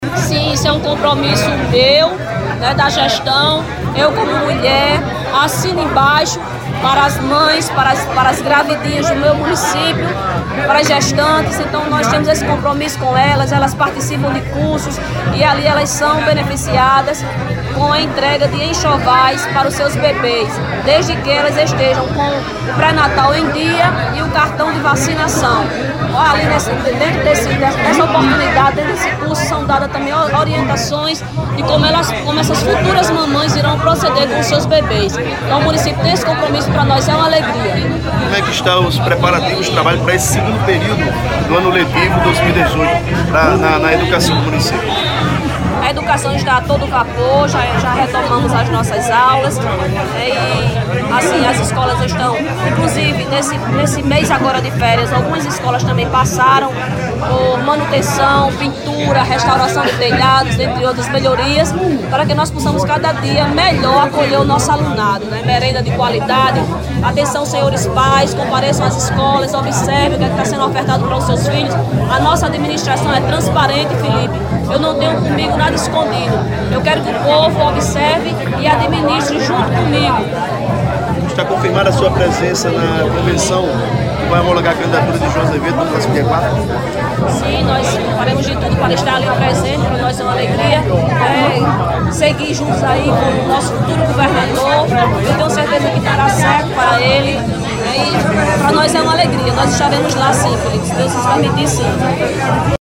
Ouça áudio 2 da prefeita Lili: